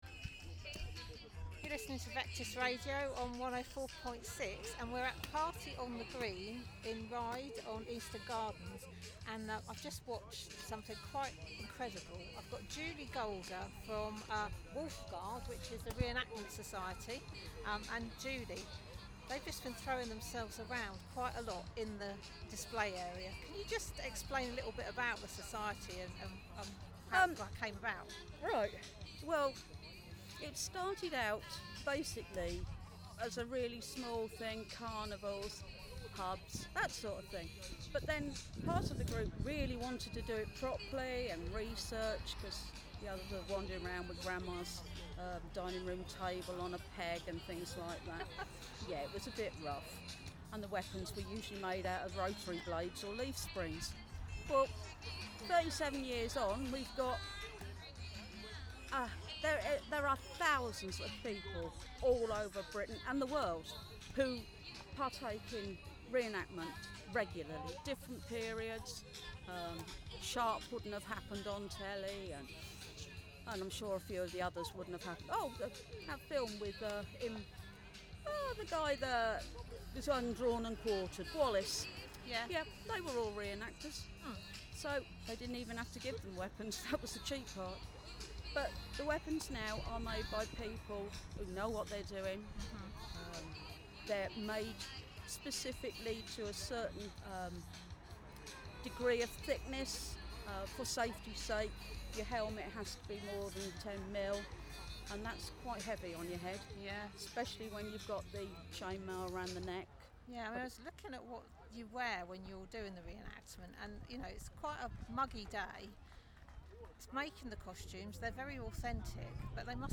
Wolfguard at the 10th Anniversary Party on the Green